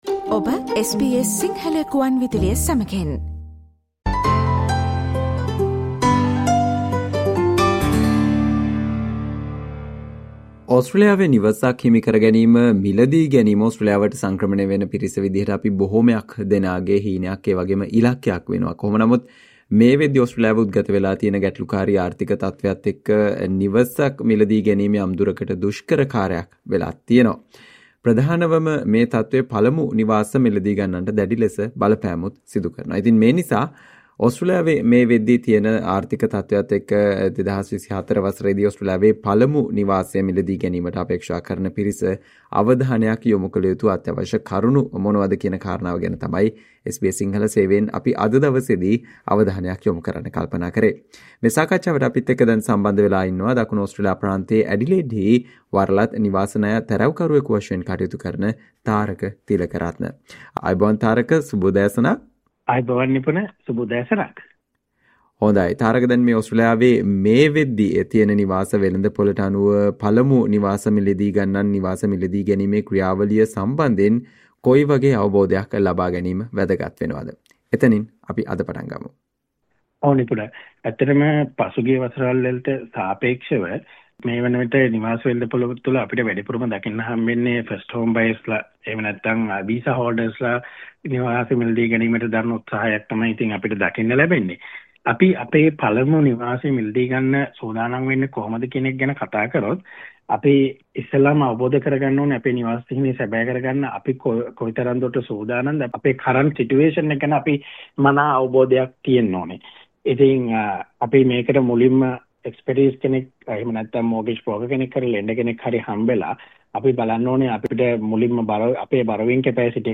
SBS Sinhala discussion on Tips to achieve your first dream home in Australia despite the current economic situation